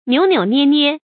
扭扭捏捏 注音： ㄋㄧㄨˇ ㄋㄧㄨˇ ㄋㄧㄝ ㄋㄧㄝ 讀音讀法： 意思解釋： 形容走路故作嬌態或有意做作。